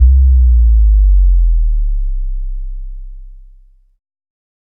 8 Slide On _Em 808 C (BASS).wav